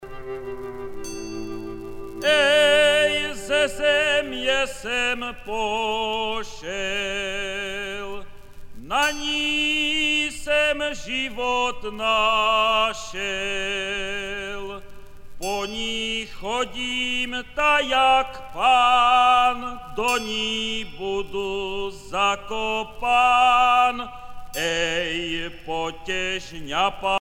Folk singer from Moravian Wallachia
Pièce musicale éditée